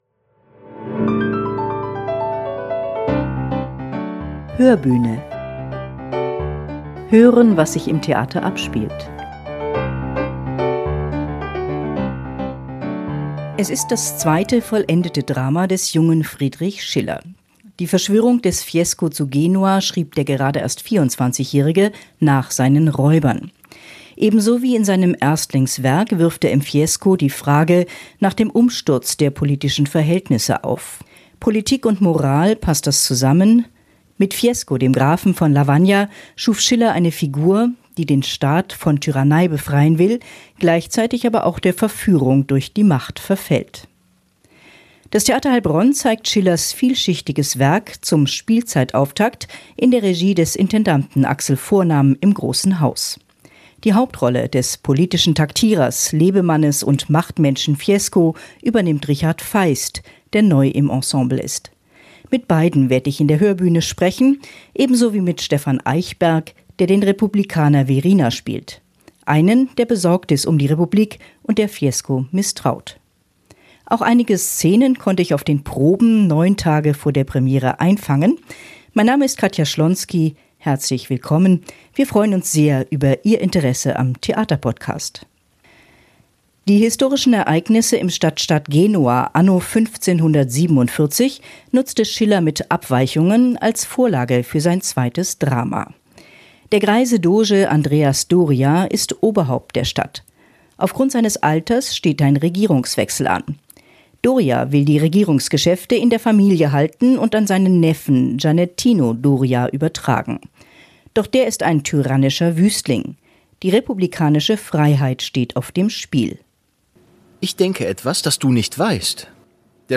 Unsere Podcasterin